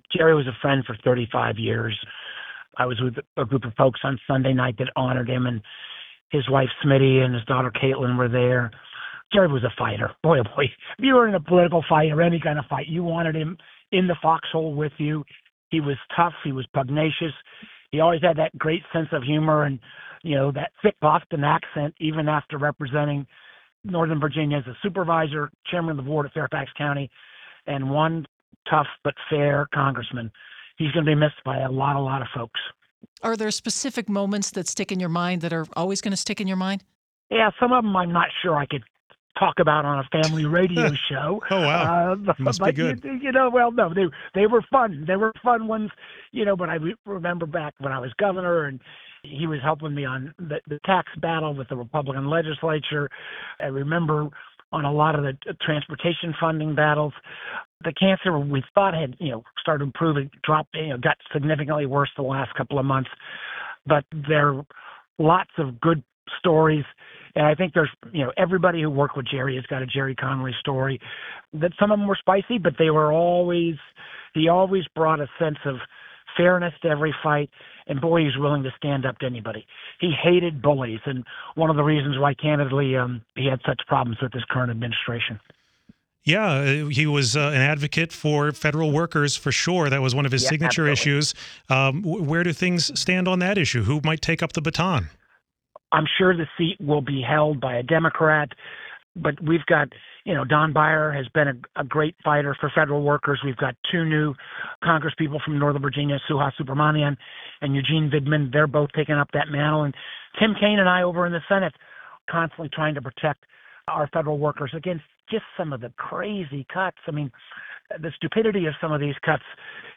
Speaking with WTOP on Wednesday morning, he called Connolly a fighter.
Sen. Mark Warner shares memories of his friend and colleague, Rep. Gerry Connolly